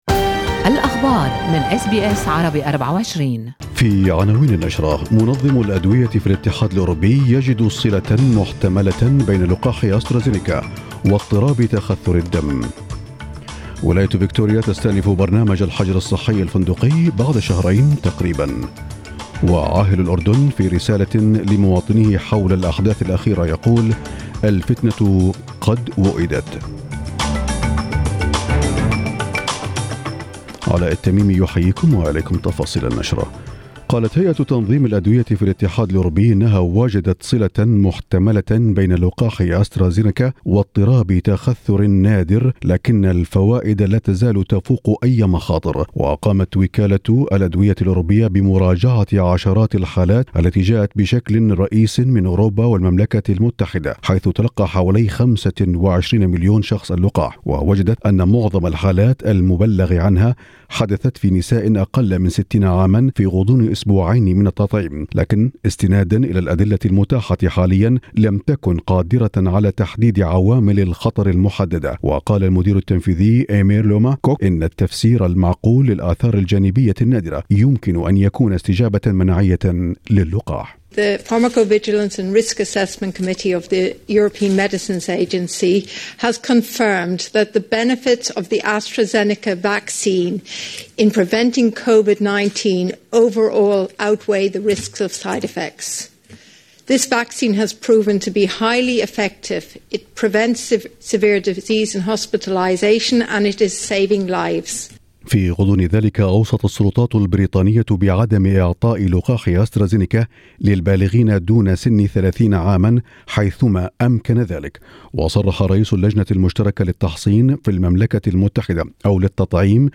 نشرة أخبار الصباح -8/4/2021